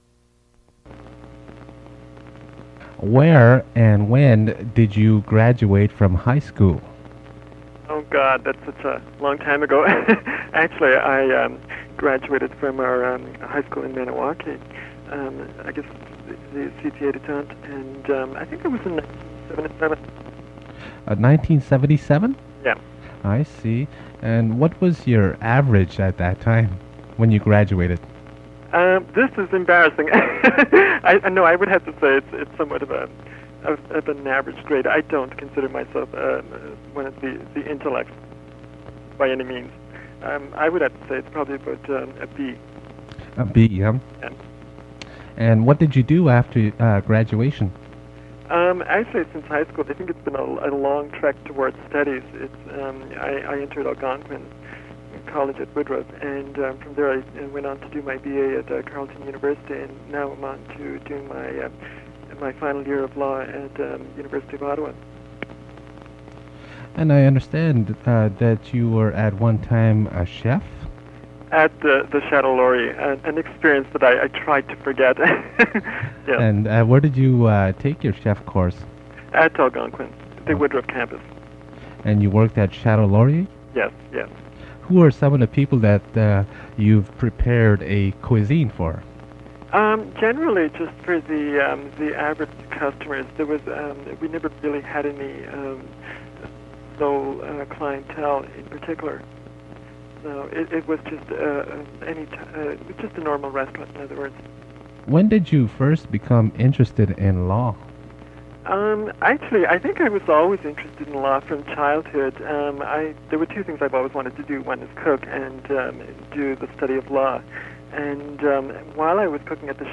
Fait partie de Interview with an Indigenous student